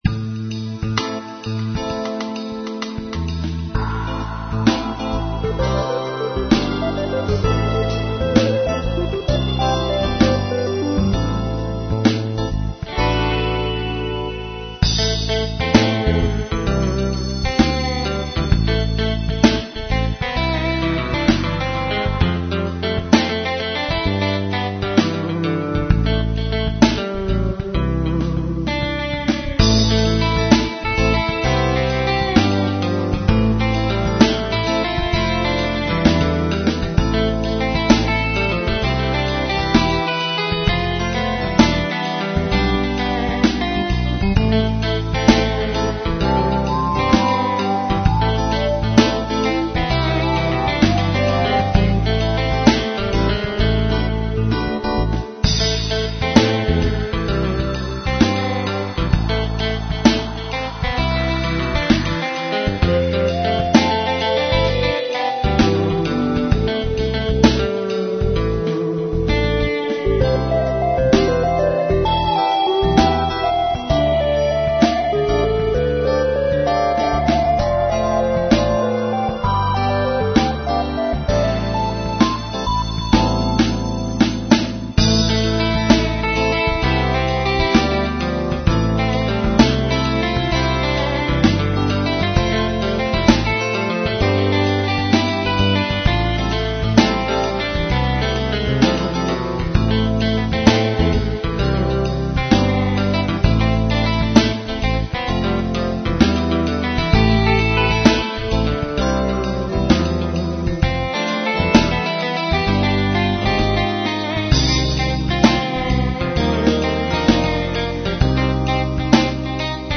Relaxed Ambiental Rock with feel of Coolness